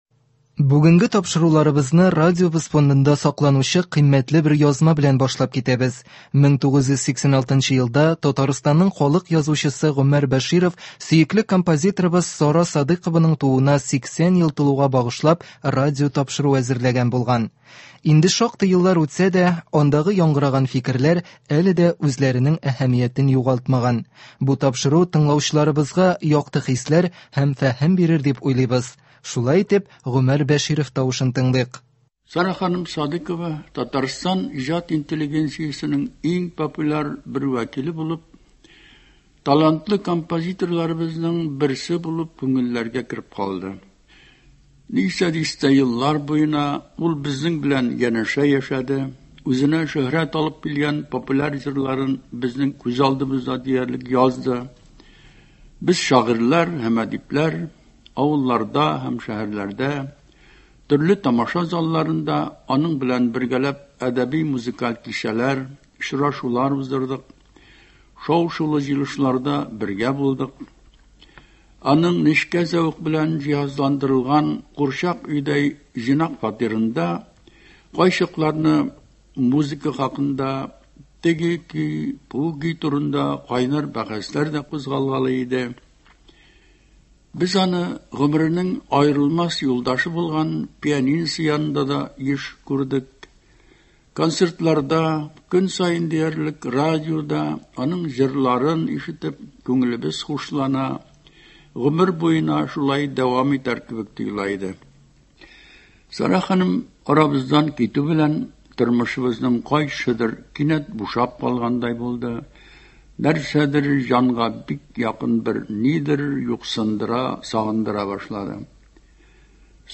Шул уңайдан 1986 елда әдип тарафыннан әзерләнгән бер тапшыруның язмасы тәкъдим ителә. Ул сөекле композиторыбыз Сара Садыйкованың тууына 80 ел тулуга багышланган булган. Әлге язма шул ягы белән дә кызыклы: биредә язучы атаклы “Җидегән чишмә” җырының язылу тарихын бәян итә.